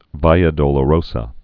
(vīə dōlə-rōsə, vēə)